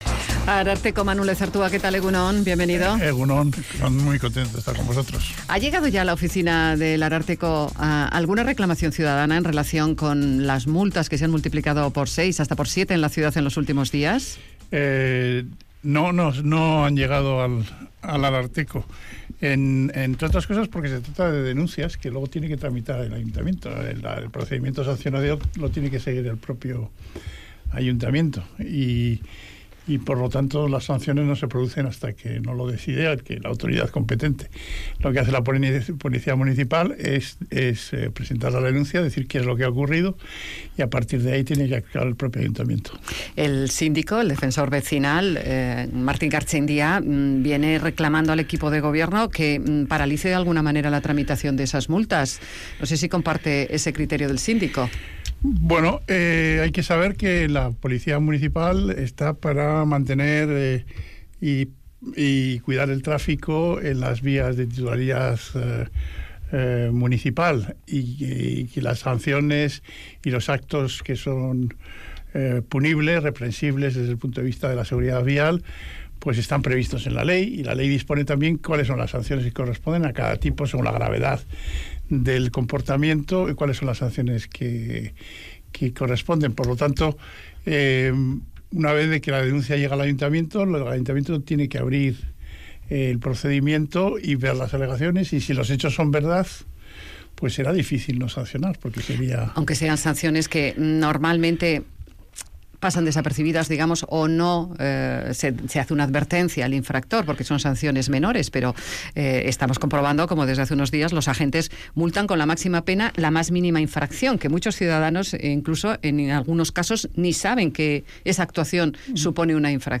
Radio Vitoria ENTREVISTAS